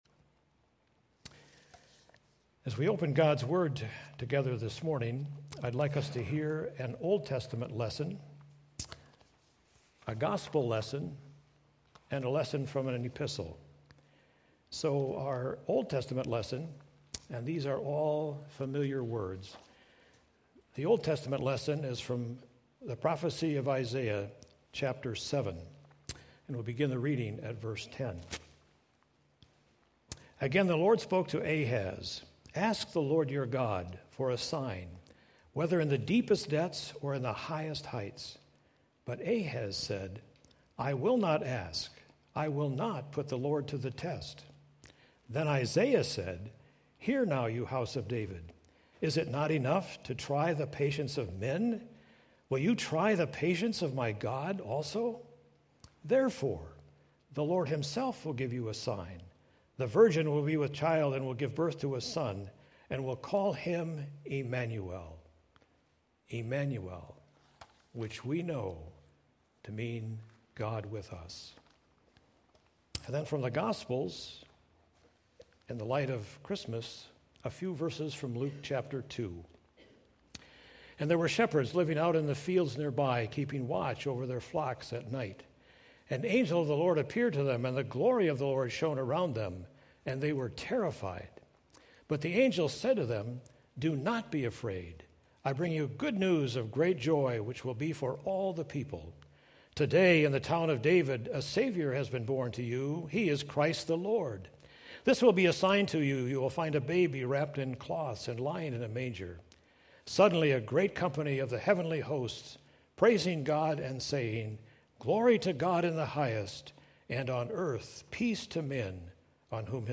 Passage: Isaiah 7:10-14, Luke 2:8-14, Hebrews 13:5-6 Worship Service Video December 29 Audio of Message « The Gift of Jesus Sabbath